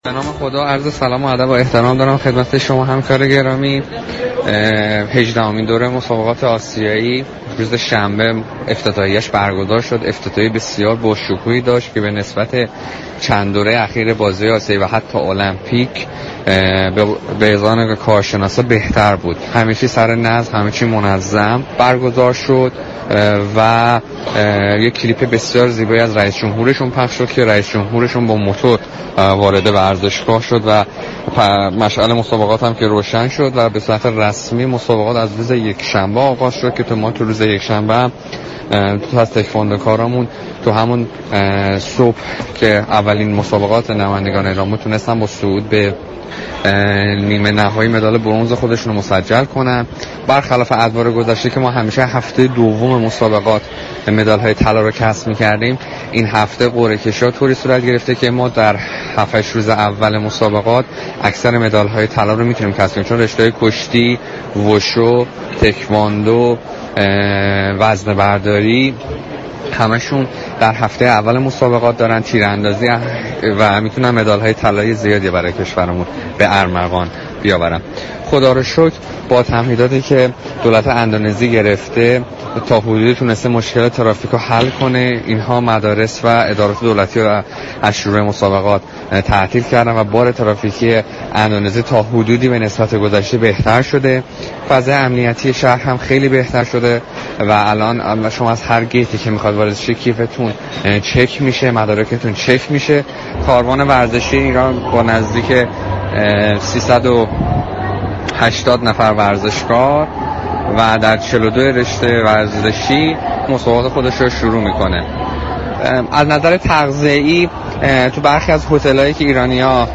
گزارشی از وضعیت تیم های ملی ایران در رقابت های آسیایی جاكارتا ارائه داد.